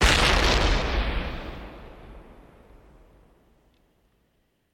TM-88 FX #07.wav